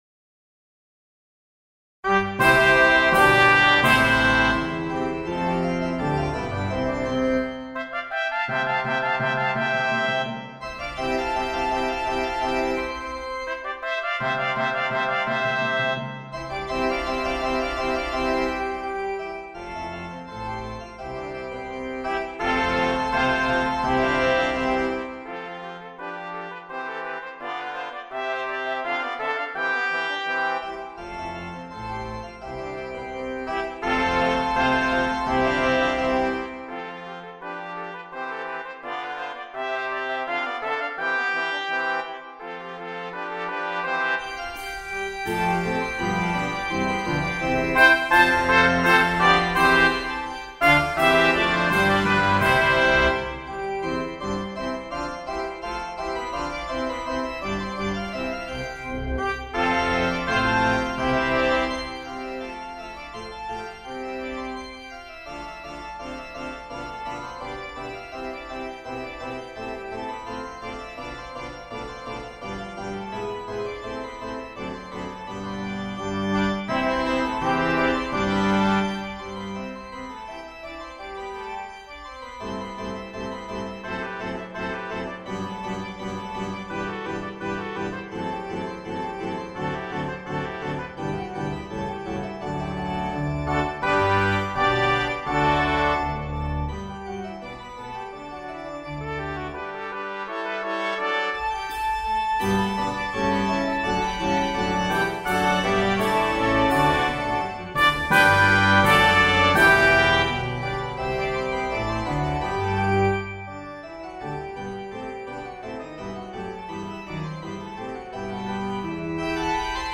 Voicing: Brass Quartet, Percussion and Organ